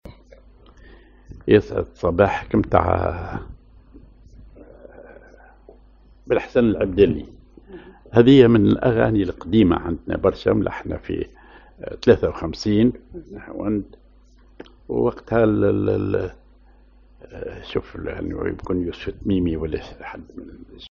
Maqam ar نها وند
Rhythm ar دويك
genre أغنية